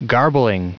Prononciation du mot garbling en anglais (fichier audio)
Prononciation du mot : garbling